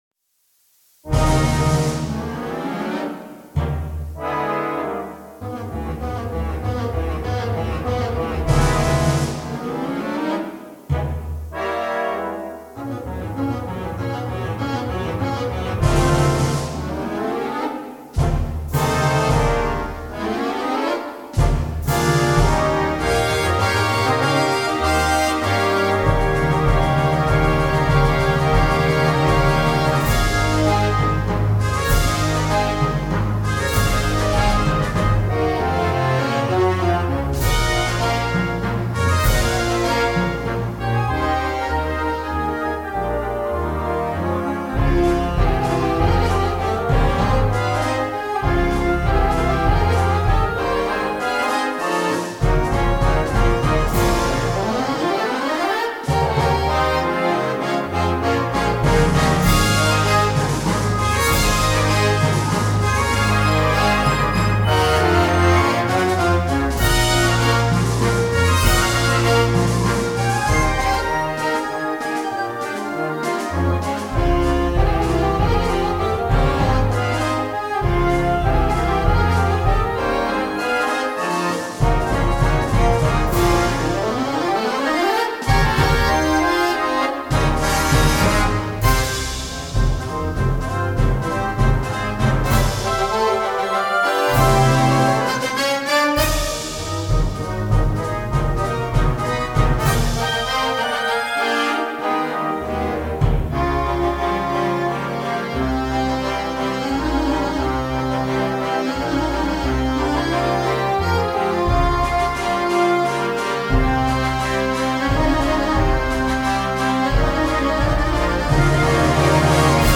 KeyAb Major
CategoryConcert Band
Piccolo
Flutes 1-2
Oboes 1-2
Bb Clarinets 1-2-3
Eb Alto Saxophones 1-2
Bb Trumpets 1-2-3
Tenor Trombones 1-2
Euphonium
Timpani
Side Drum / Cymbals / Bass Drum